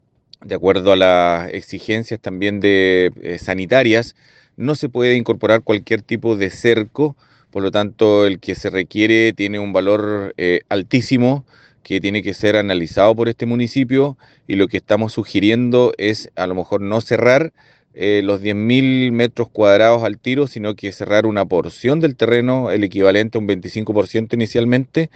Consultado por Radio Bío Bío, el alcalde reelecto de Lago Ranco, Miguel Meza, confirmó que hicieron las gestiones para sanear el terreno y reconoció que está pendiente el cierre perimetral, añadiendo que debido al costo de ese trabajo, evalúan en primera instancia poner el cerco solo en un cuarto del terreno destinado al cementerio.
alcalde-cementerio-.mp3